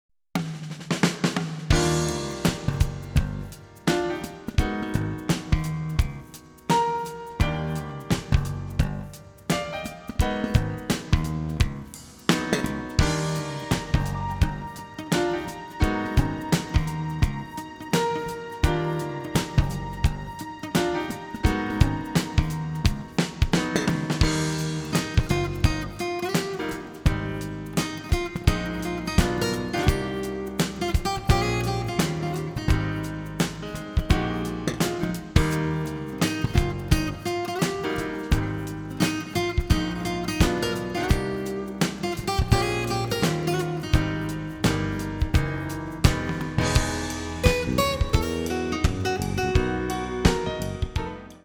Håll i er, det är mässjazz!
¤ TR-org.wav är rippen på skivan, rippad med sound forge.